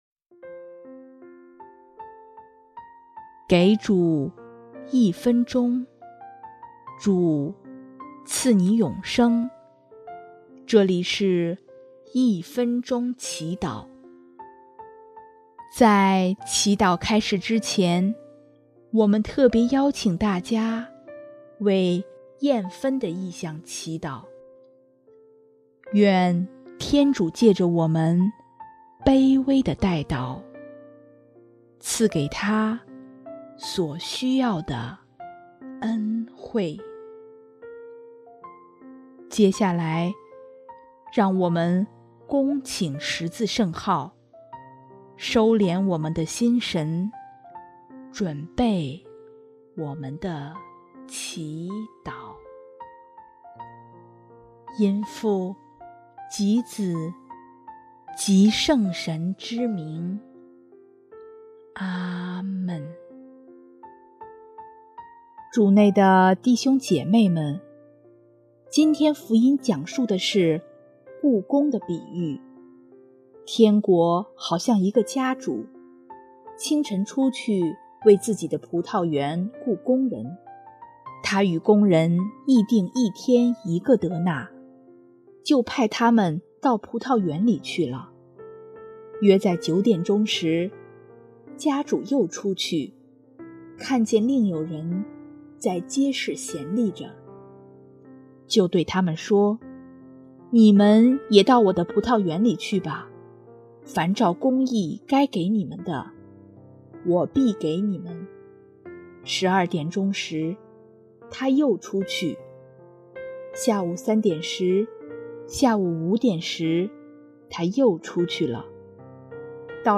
【一分钟祈祷】|8月23日 思主之所念，勤吾之所工！